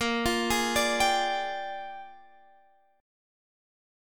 A#13 Chord